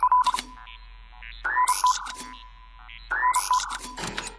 • SYNTHESIZED BEEP.wav
SYNTHESIZED_BEEP_h2Q.wav